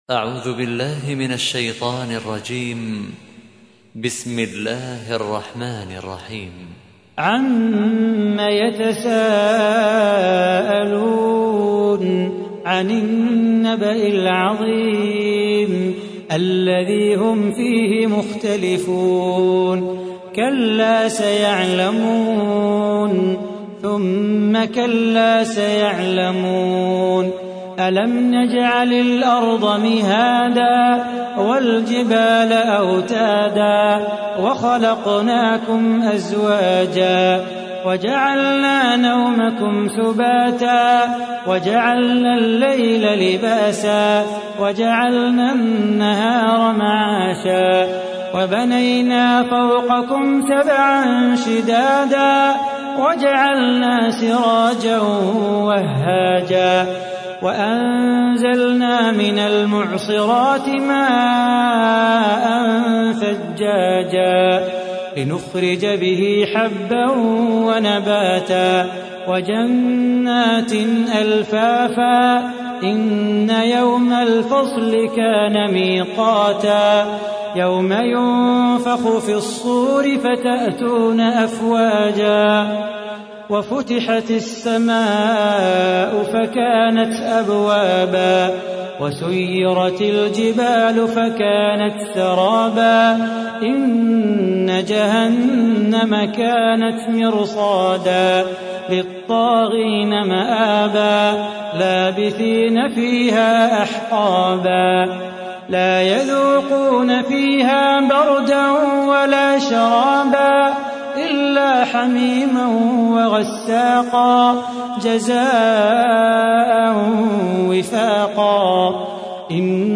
تحميل : 78. سورة النبأ / القارئ صلاح بو خاطر / القرآن الكريم / موقع يا حسين